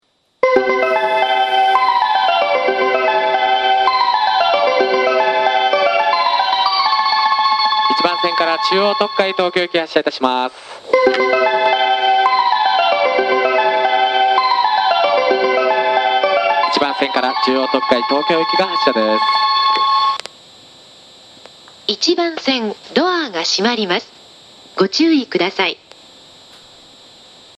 2010年2月1日までの自動放送
発車メロディー
1.9コーラスです!
全列車が始発なので、余韻まではよく鳴ります。